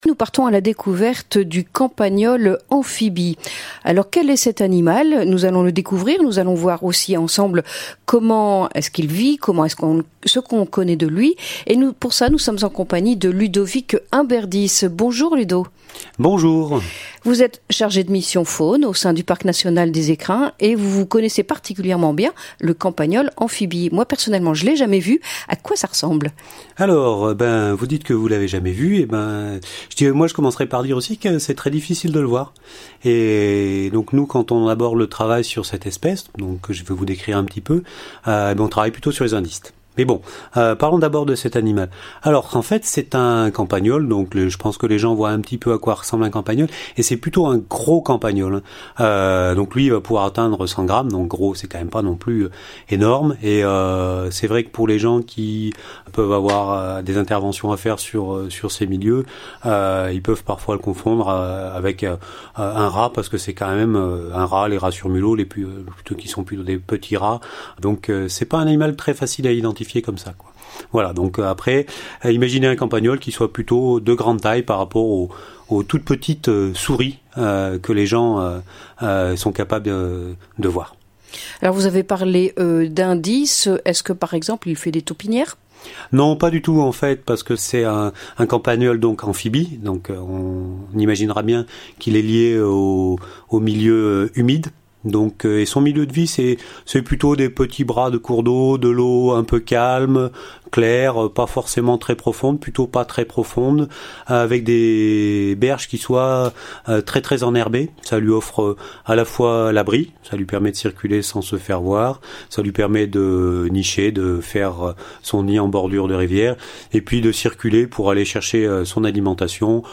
Chronique nature C'est un animal d 'environ 100 grammes qui vit au bord de petits cours d'eau et de canaux d'irrigation, dissimulé dans de hautes herbes dont il se nourrit.